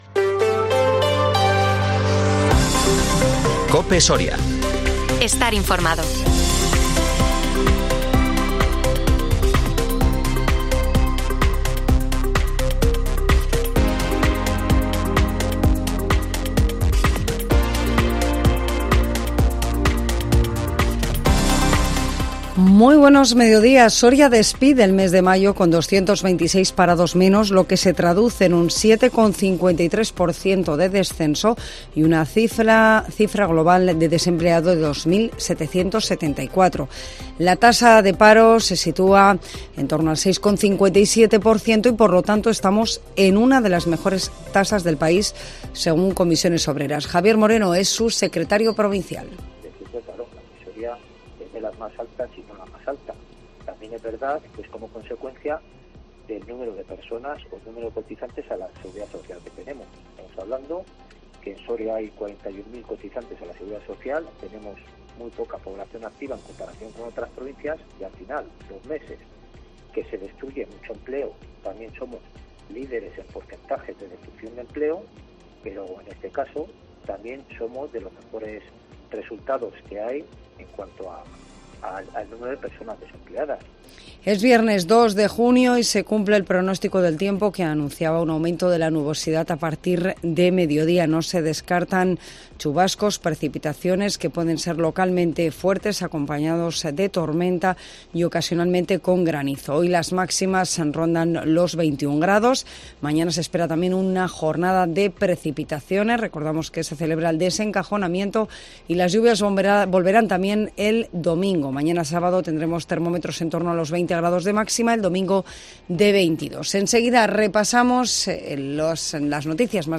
INFORMATIVO MEDIODÍA COPE SORIA 2 JUNIO 2023